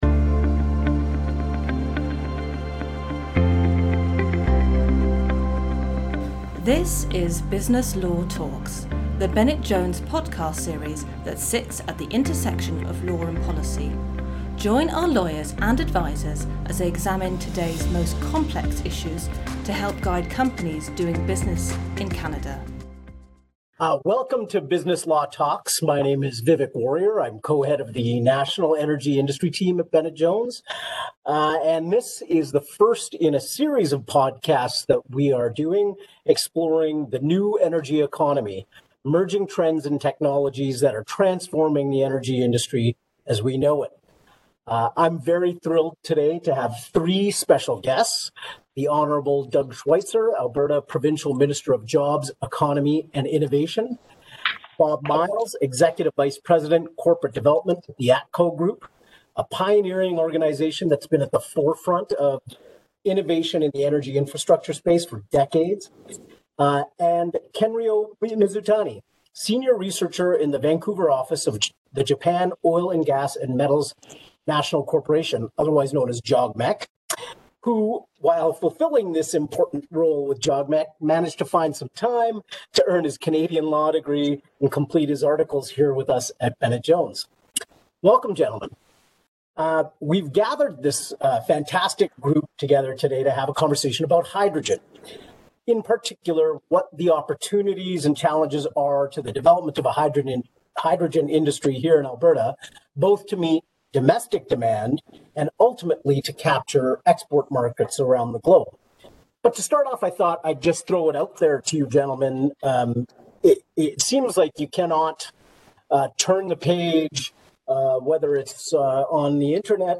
anime une discussion avec trois invités spéciaux